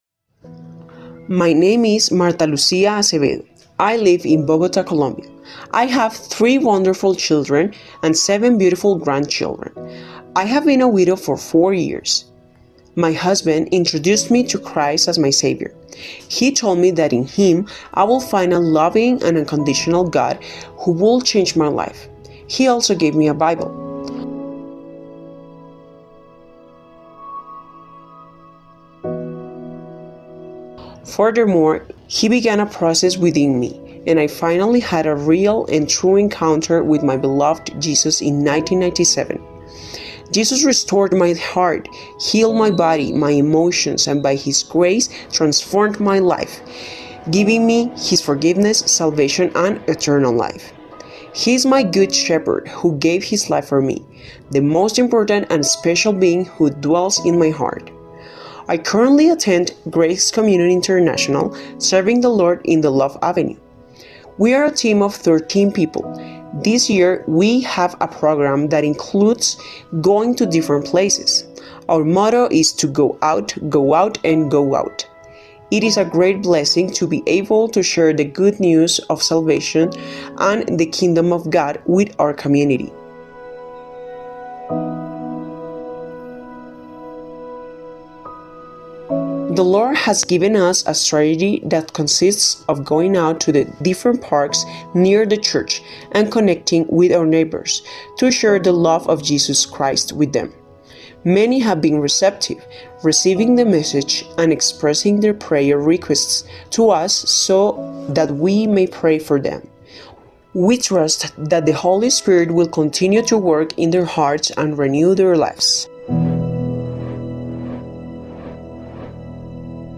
Note: The original testimony was shared in Spanish and has been dubbed in English.